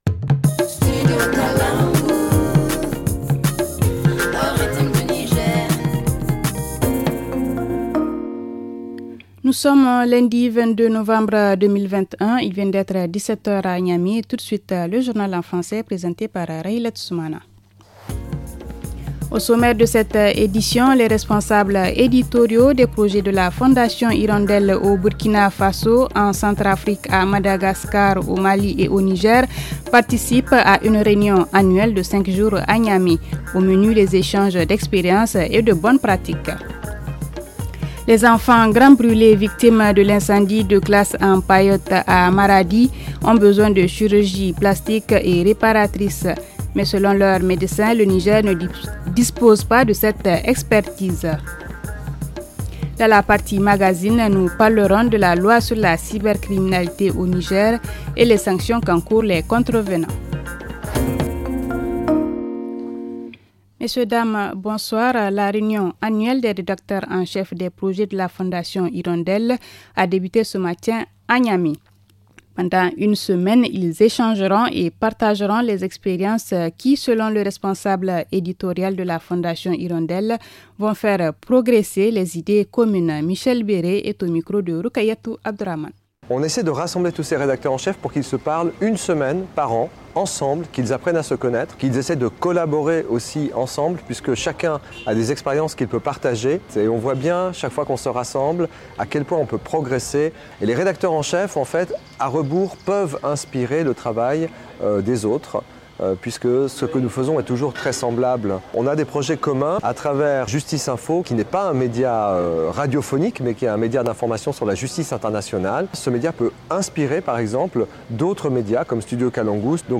Le journal du 22 novembre 2021 - Studio Kalangou - Au rythme du Niger